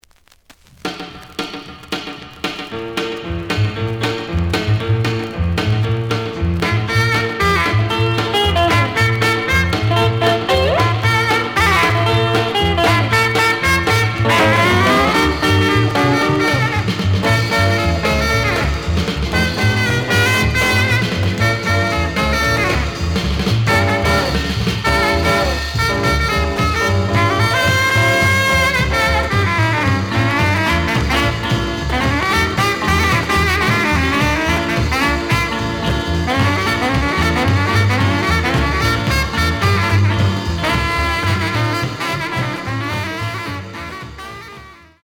The audio sample is recorded from the actual item.
●Genre: Rhythm And Blues / Rock 'n' Roll
Some periodic noise on first half of both sides.)